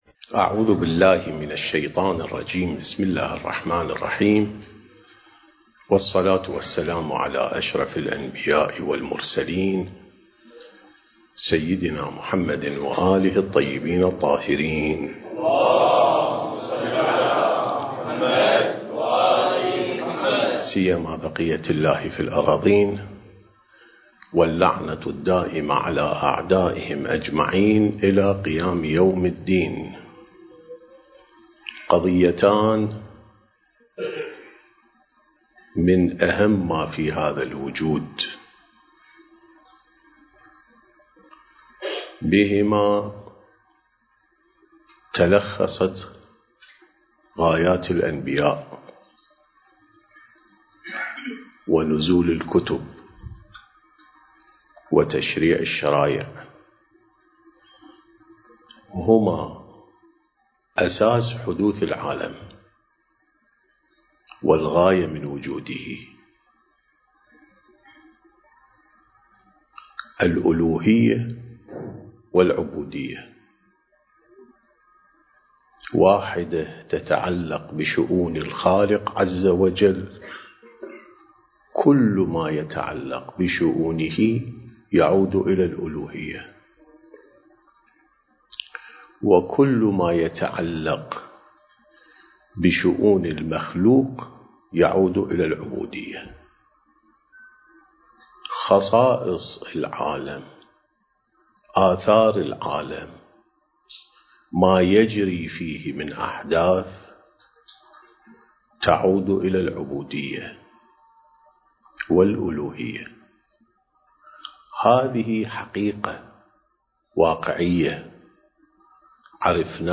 سلسلة محاضرات: الإعداد الربّاني للغيبة والظهور (1)